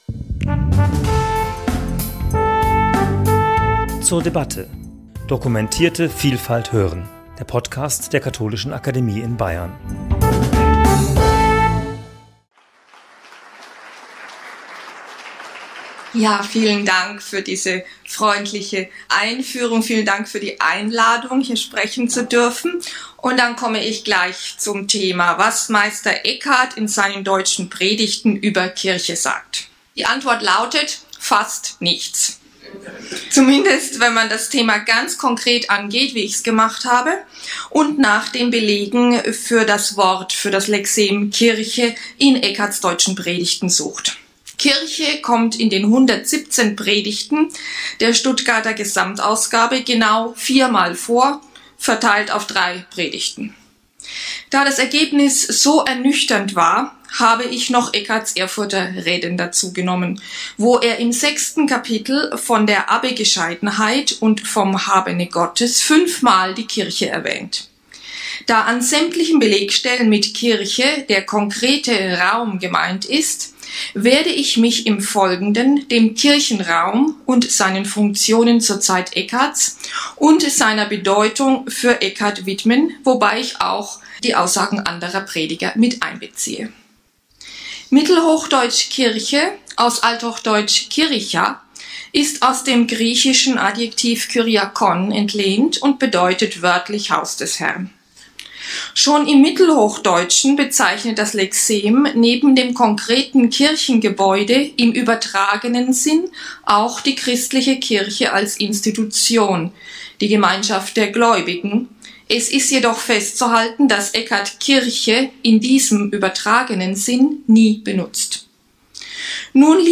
referierte am 16.3.2024 in der Katholischen Akademie in Bayern zu dem Thema